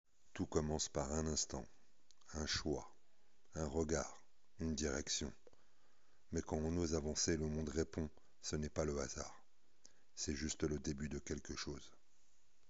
Voix off Grave, possée